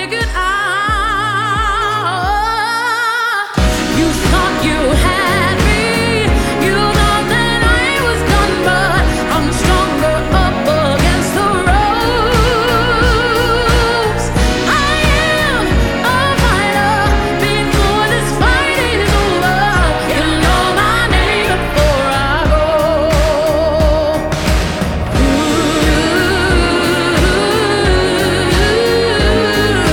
• Soul